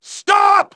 synthetic-wakewords
ovos-tts-plugin-deepponies_Demoman_en.wav